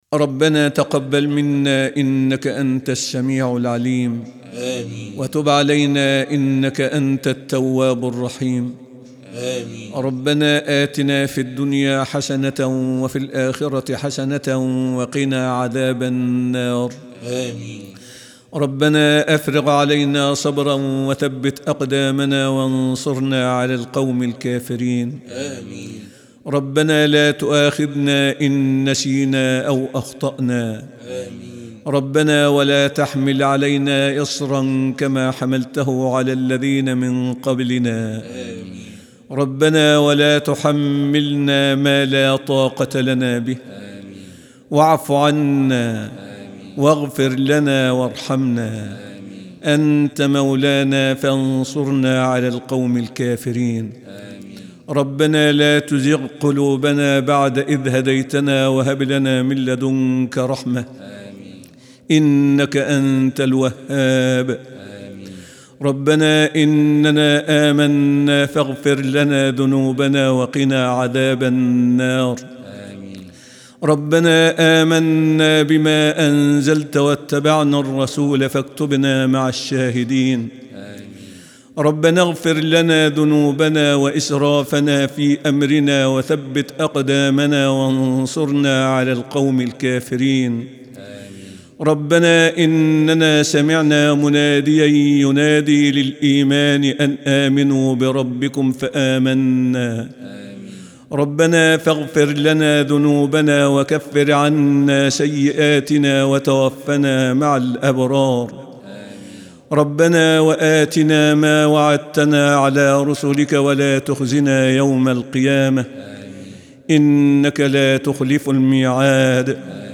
أدعية وأذكار